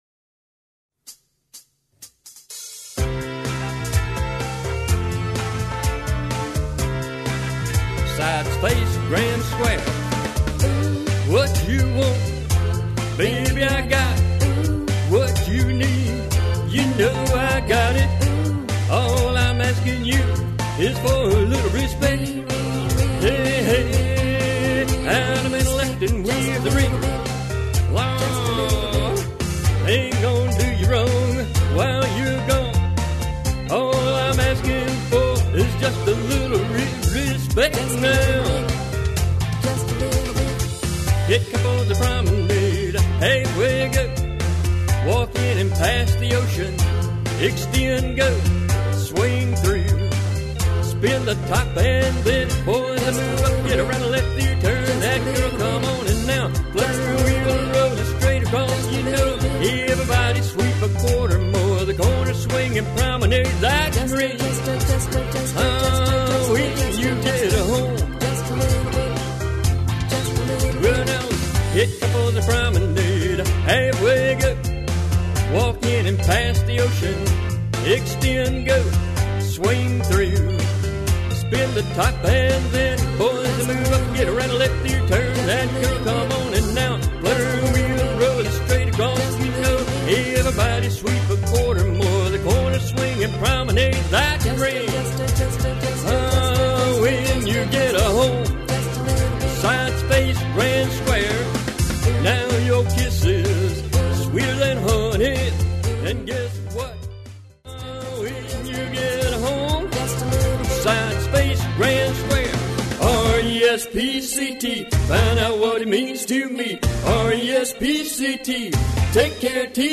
Singing Call